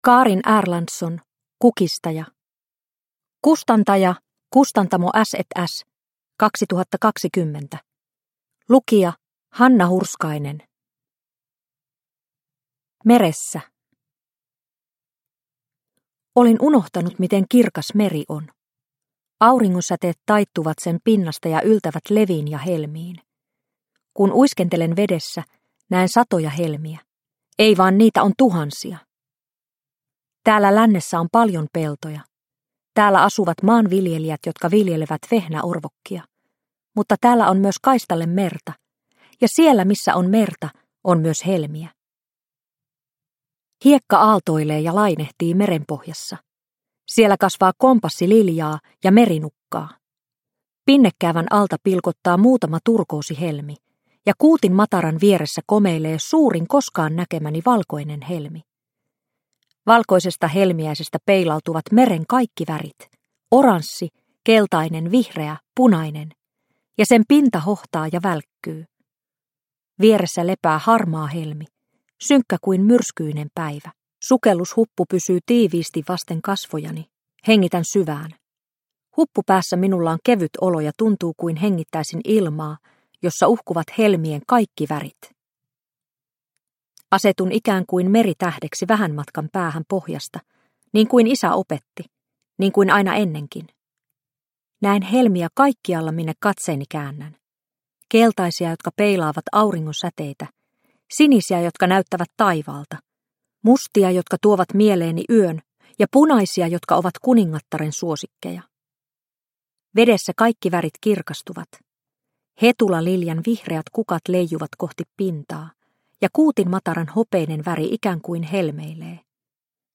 Kukistaja – Ljudbok – Laddas ner